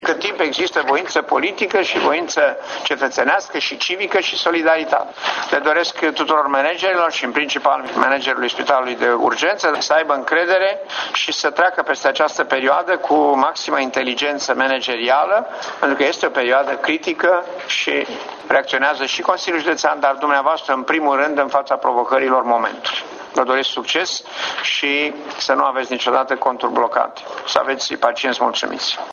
Preşedintele Silvian Ciupercă a precizat în plenul şedinţei că urmează o perioadă critică pentru managerii spitalelor din judeţ: